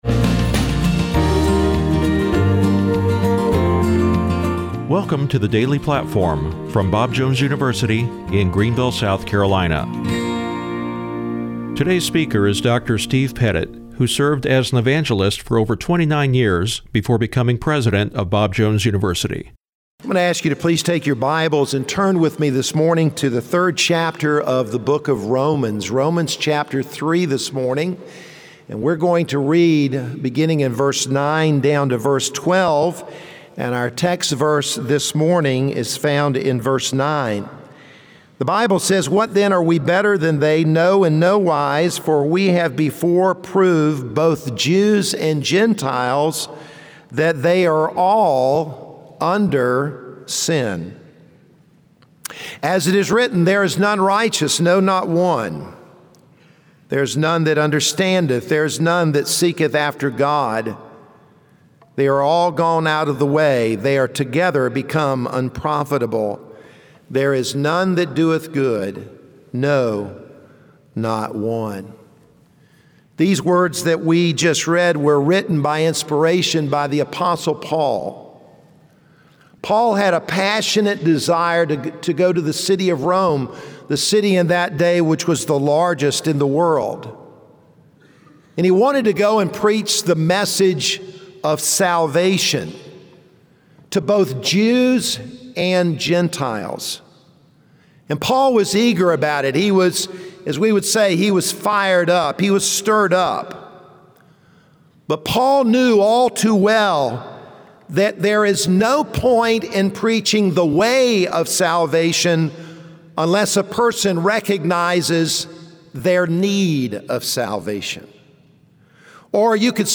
brings a chapel message from Romans 3:9